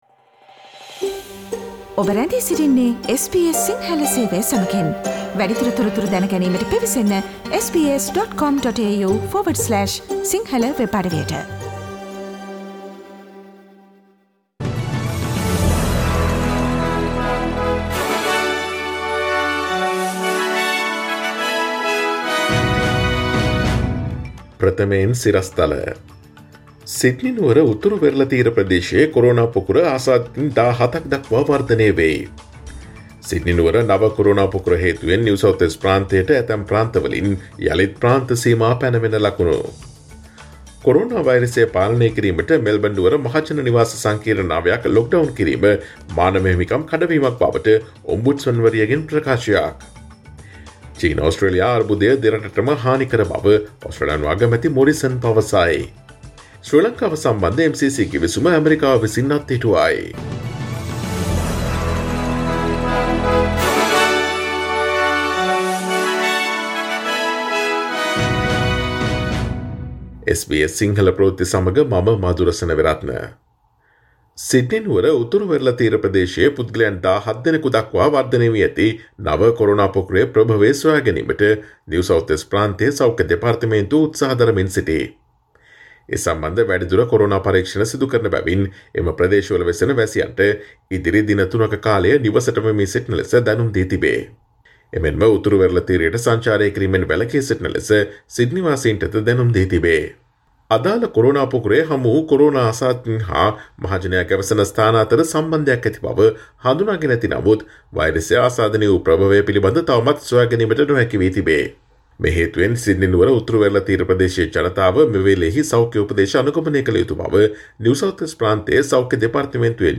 Today’s news bulletin of SBS Sinhala radio – Friday 18 December 2020